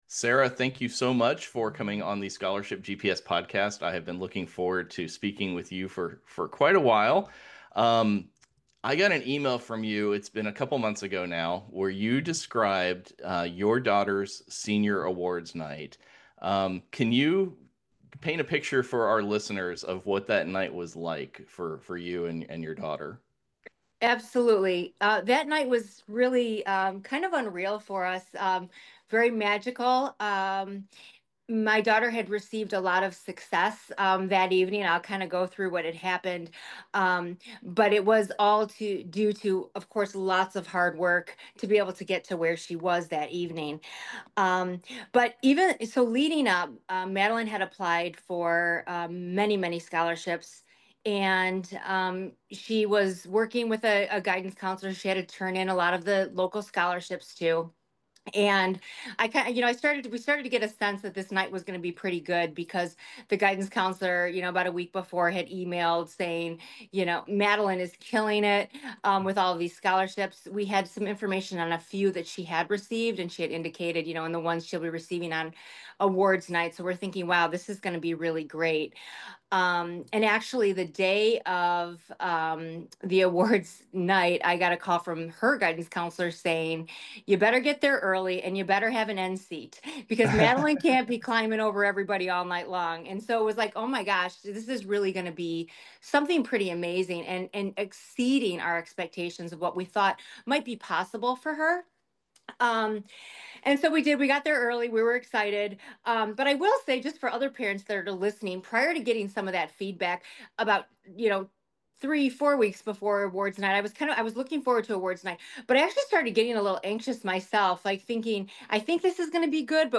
During our discussion, we talked about: